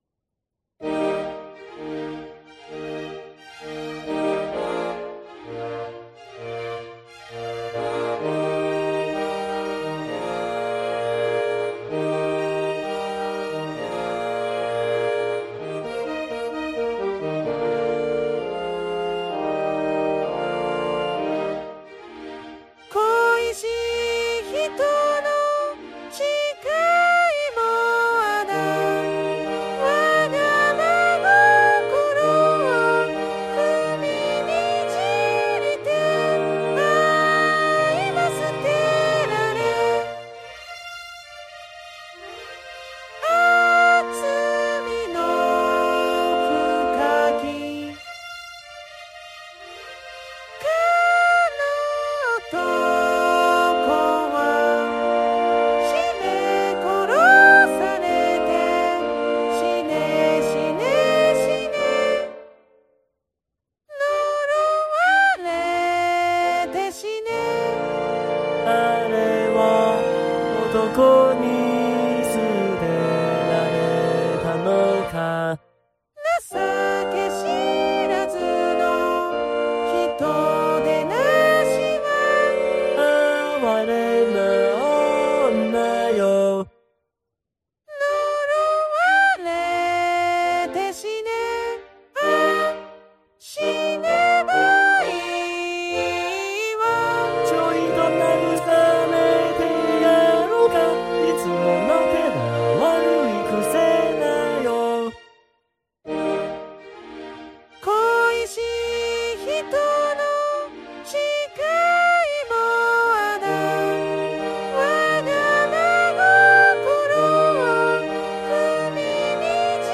男声はGackpoidかKaitoを、女声は初音ミクNTを使いました。
管弦楽はGarritan Personal Orchestra5(VST)を使ってMP3形式で保存したものです。